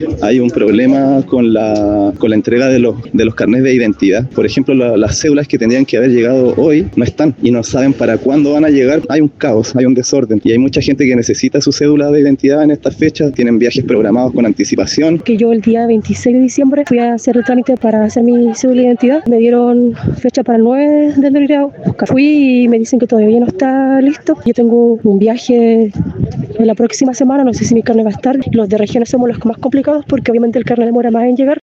Por otro lado, auditores de La Radio denunciaron que, a pesar de tener una fecha de entrega fijada, al momento de retirar el documento se les comunicó que aún no estaba disponible.